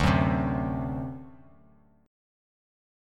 D7 Chord
Listen to D7 strummed